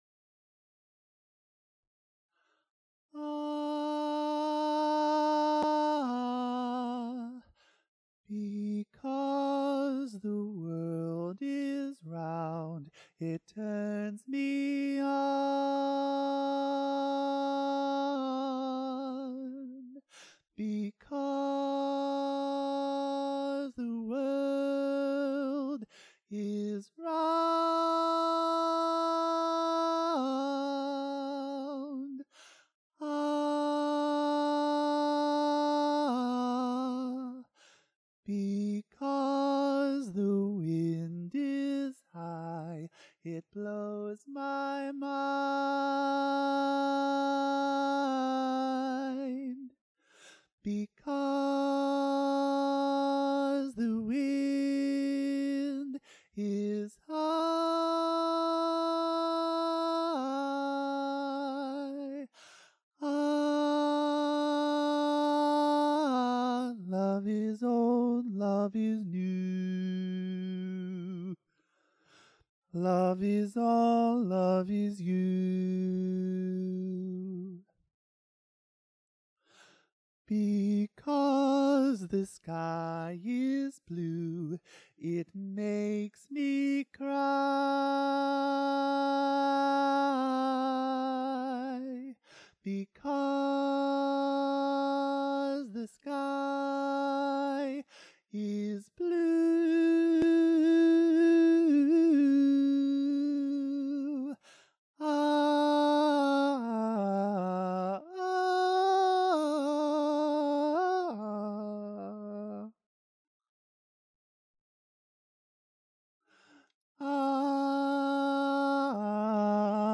Bass Lyrical